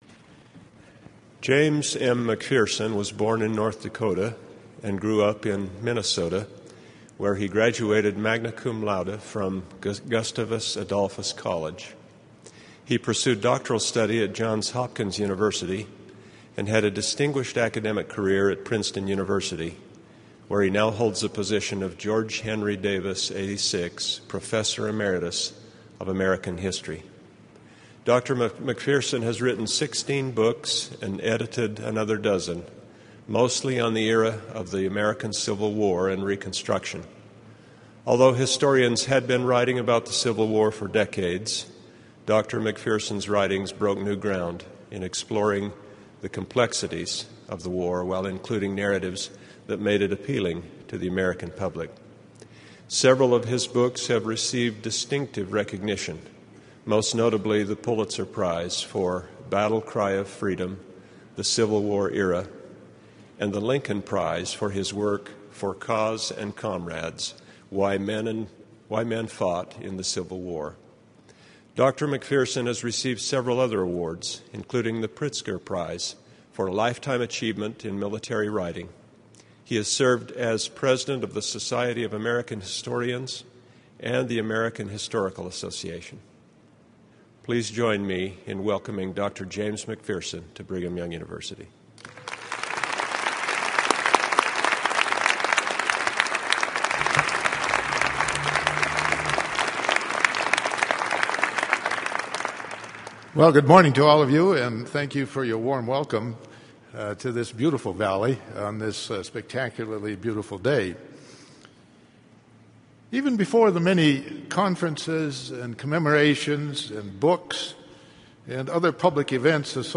Civil War historian James McPherson details pivotal historic events that show how and why the Civil War still matters today.
Forum